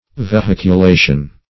\Ve*hic`u*la"tion\